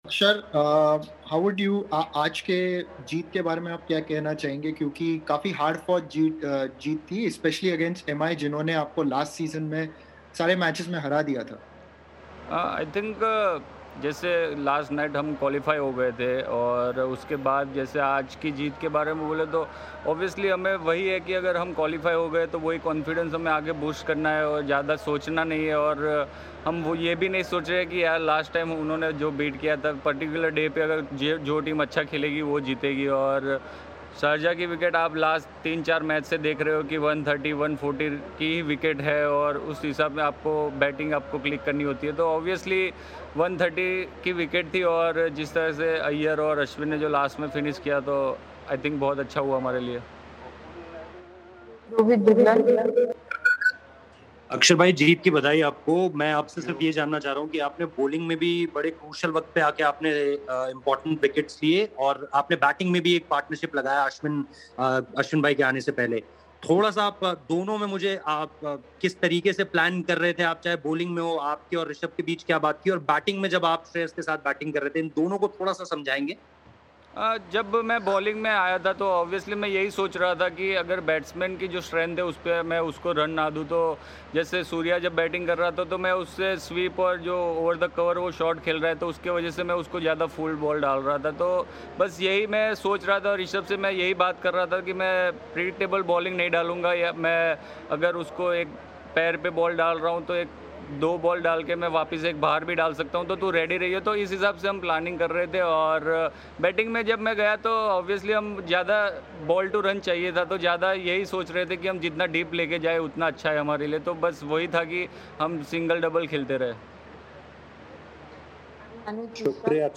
Axar Patel speaks to the media after Delhi Capitals win by 4 wickets
Axar Patel of Delhi Capitals addressed the media after the game.